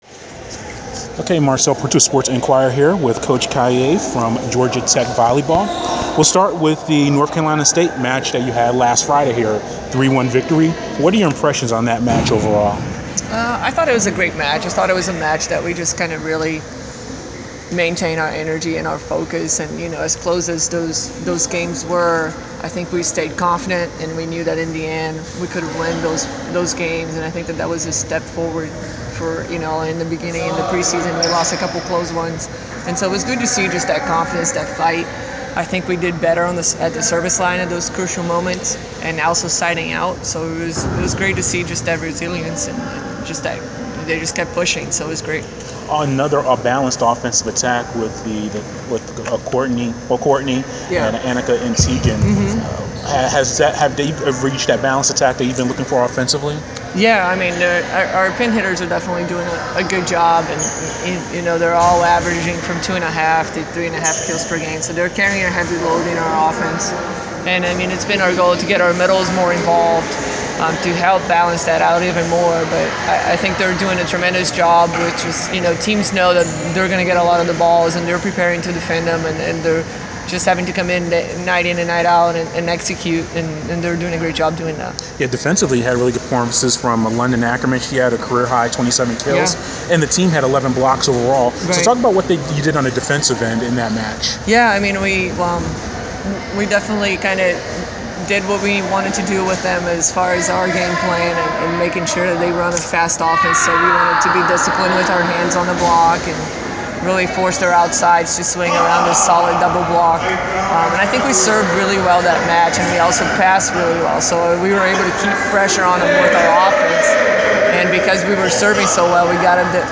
Inside Georgia Tech: Interview